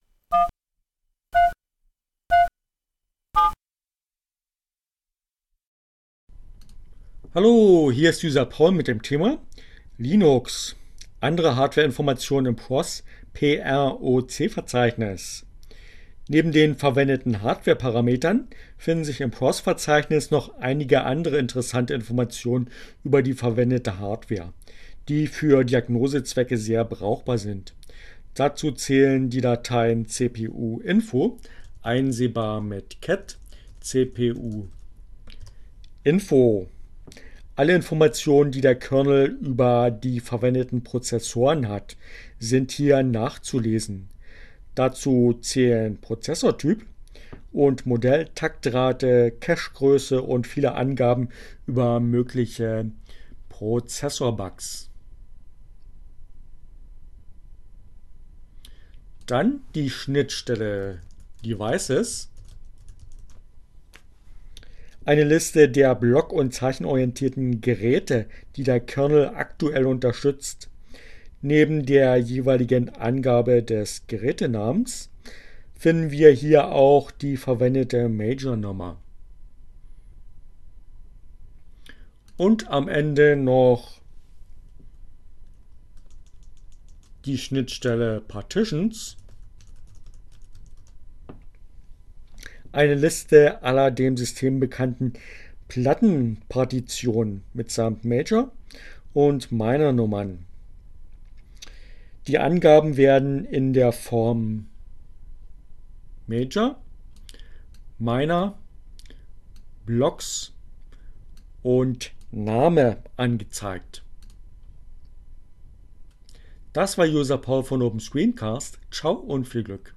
Tags: CC by, Linux, Neueinsteiger, ohne Musik, screencast, proc